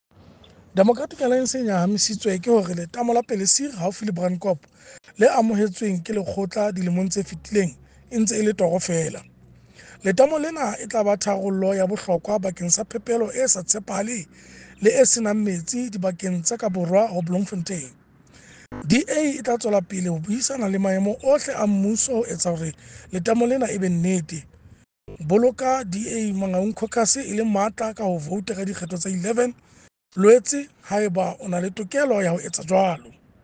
Sesotho by Cllr Kabelo Moreeng.
Sotho-voice-Kabelo.mp3